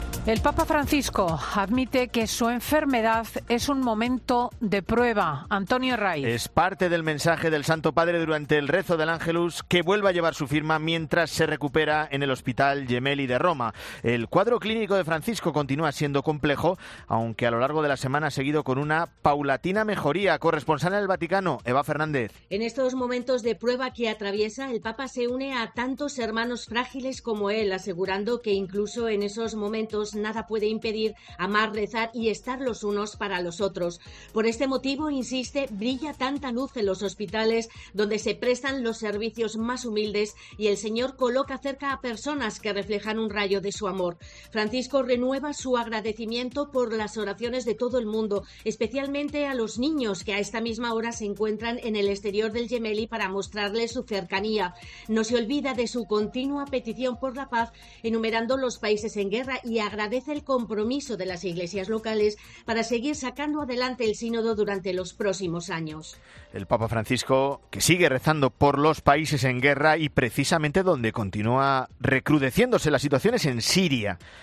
corresponsal en Roma y el Vaticano